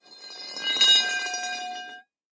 Звуки лома
Везут по гладкому асфальту